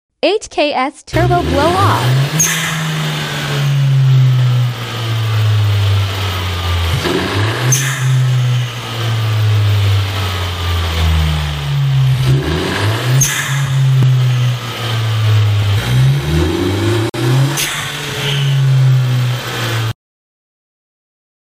HKS SQV TURBO BLOW OFF Sound Effects Free Download